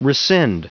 Prononciation du mot rescind en anglais (fichier audio)
Prononciation du mot : rescind